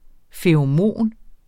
Udtale [ feɐ̯oˈmoˀn ]